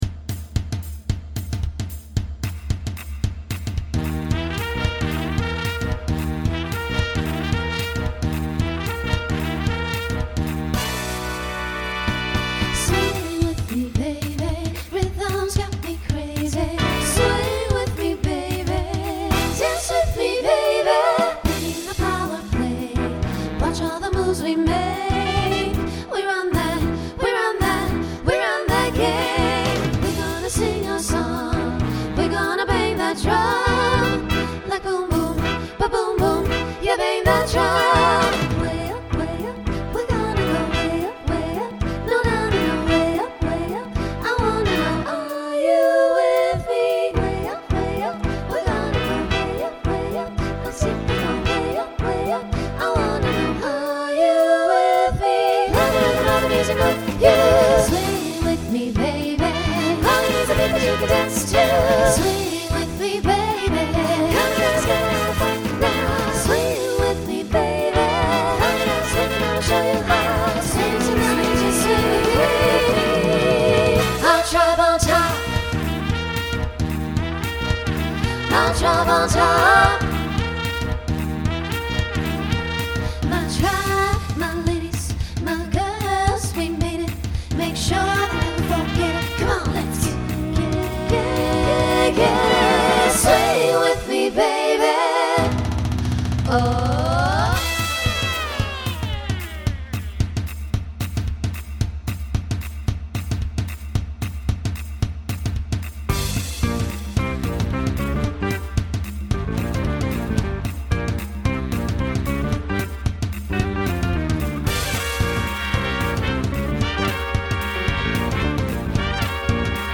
Voicing SSA Instrumental combo Genre Swing/Jazz
2010s Show Function Mid-tempo